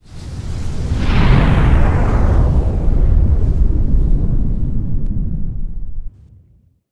KlingonExitWarp.wav